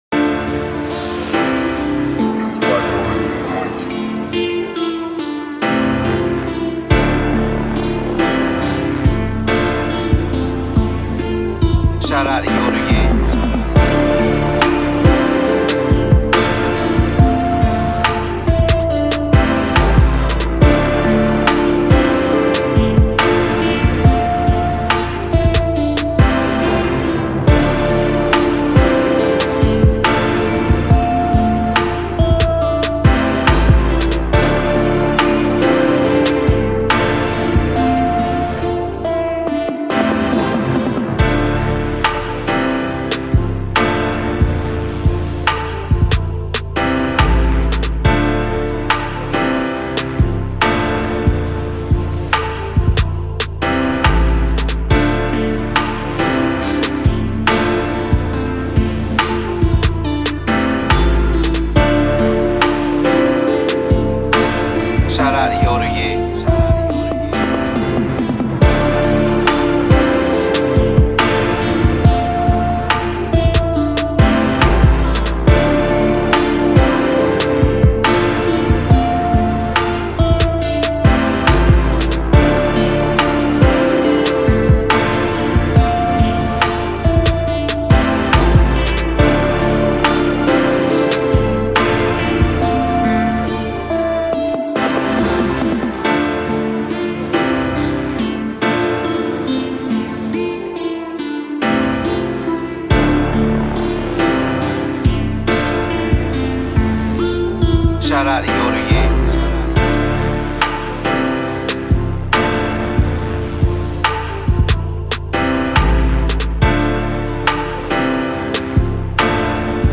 Rap Instrumental